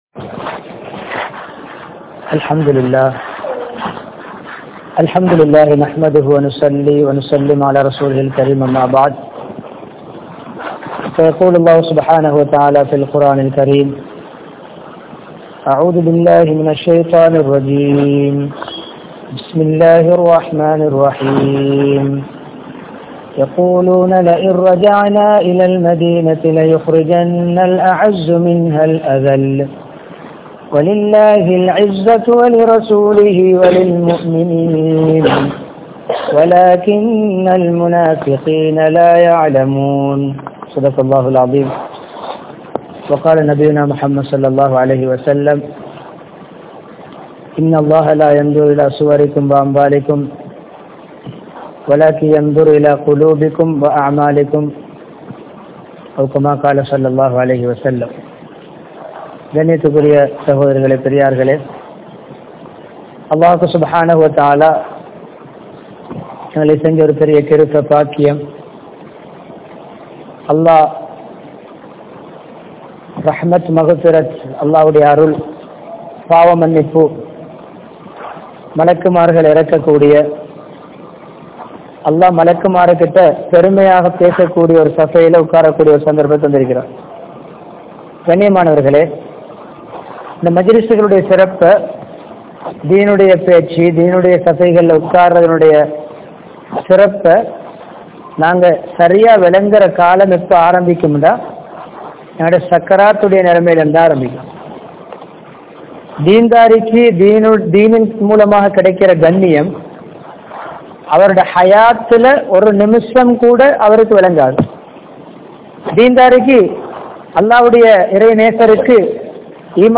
Mumeenin Ganniyam (முஃமினின் கண்ணியம்) | Audio Bayans | All Ceylon Muslim Youth Community | Addalaichenai
Kinniya, Faizal Nagar, Koofah Masjidh